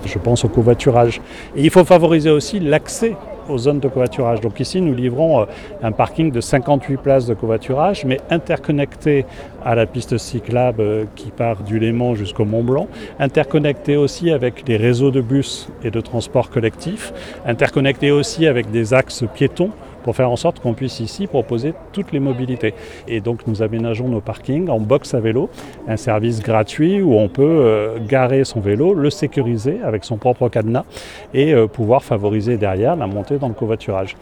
Ces travaux améliorent la sécurité et la fluidité du trafic grâce à la création de deux nouveaux giratoires qui remplacent les feux tricolores. Et grâce à la création d’un nouveau parking de covoiturage, d’un arrêt de bus, et d’une voie cyclable, les modes de déplacements plus durables sont encouragés comme l’explique Christophe Castaner, président de la société Autoroutes et tunnel du Mont-Blanc (ATMB).